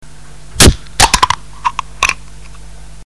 Bierdosen - Recycling 53 KB 1553 Sound abspielen!
dosen_recycling.mp3